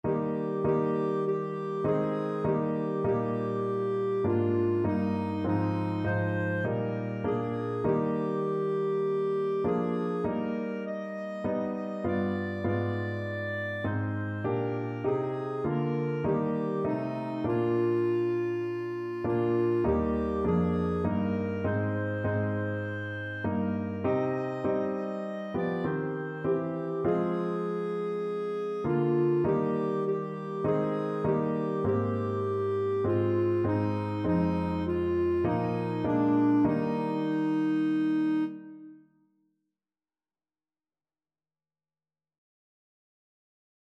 Christian
4/4 (View more 4/4 Music)
Classical (View more Classical Clarinet Music)